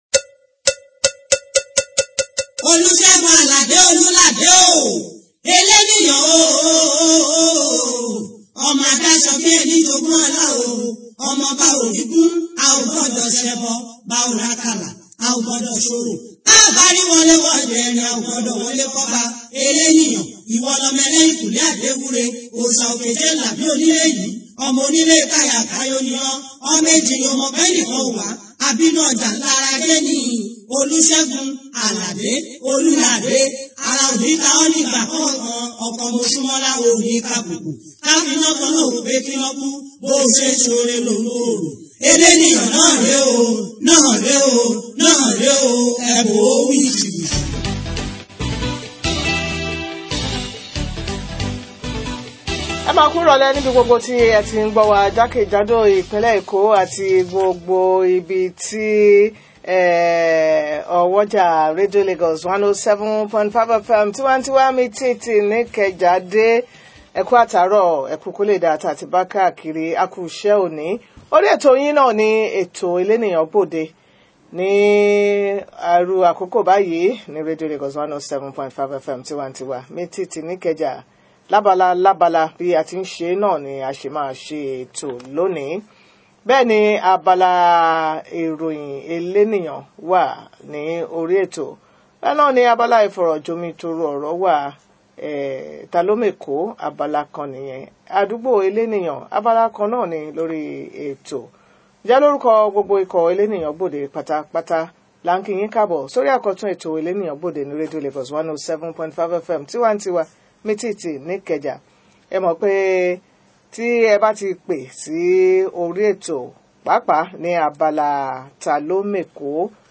Guest: Mr Kehinde Bamgbetan – Special Adviser to Gov. Ambode on Communities & Communications